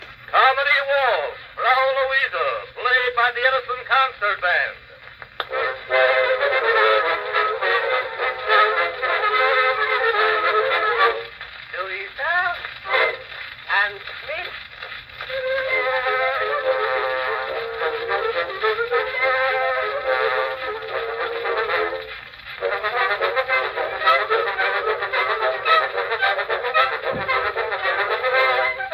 Fireside-With-Papier-Mache-Horn.mp3